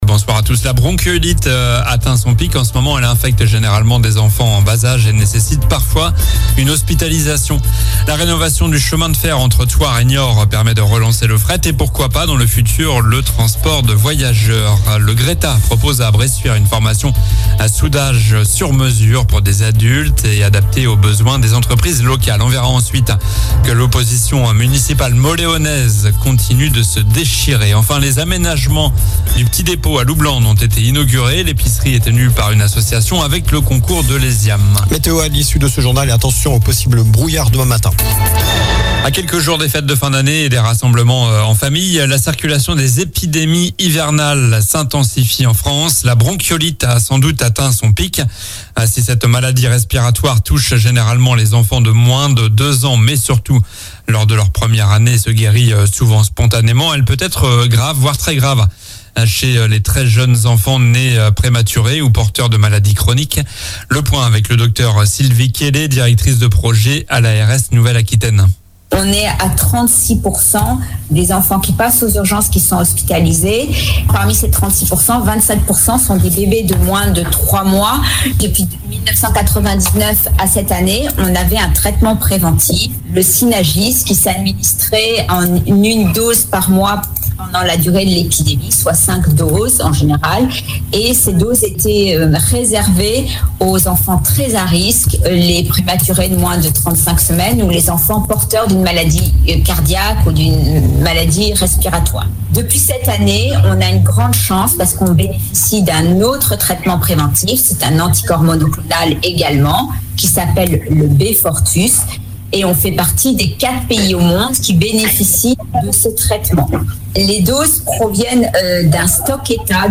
Journal du jeudi 14 Décembre (soir)